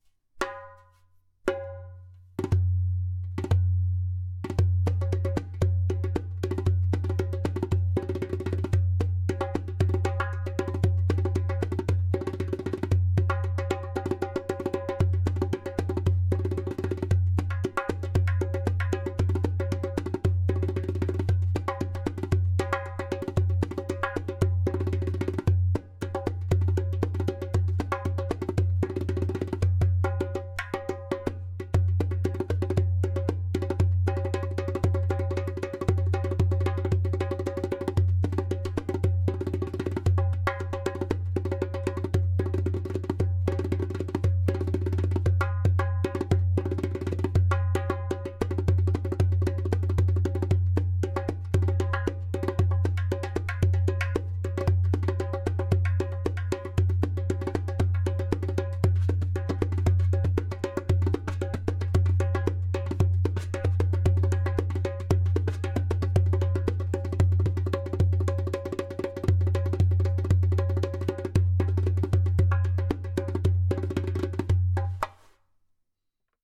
115 BPM:
• Strong and easy to produce clay kik (click) sound
• Deep bass
• Beautiful harmonic overtones.